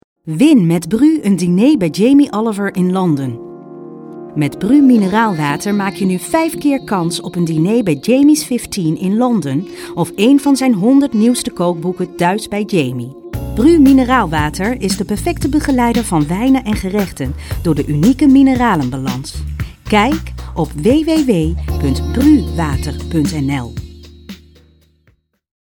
Suchen sie ein freundliche und warme stimme, jung/mittel fur ihren videoprestation, commercial, gesprochenen buch, jingle, e- learning und soweiter dan kann ich moglich etwas fur sie tun.
Sprechprobe: Industrie (Muttersprache):